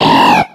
Cri de Barloche dans Pokémon X et Y.